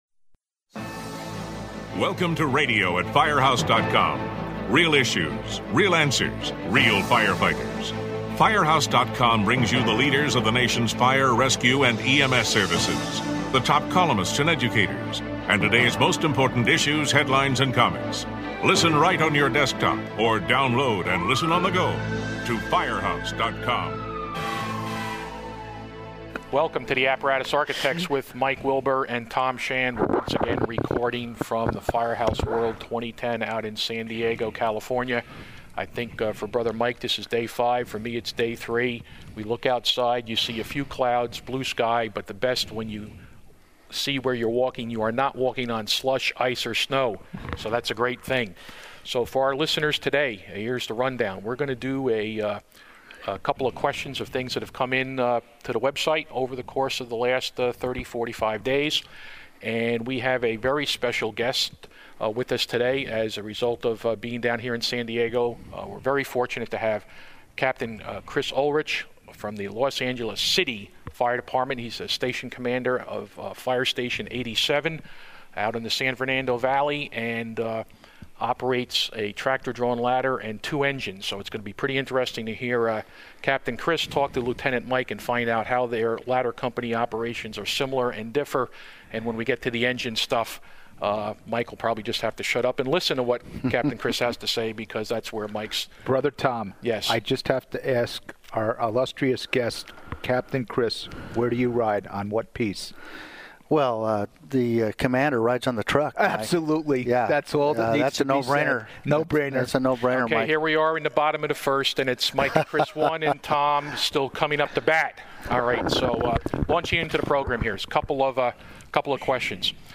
The Apparatus Architects discuss the dangers of backing up apparatus and how often firefighters are injured or killed during backing incidents. This podcast was recorded at Firehouse World in San Diego.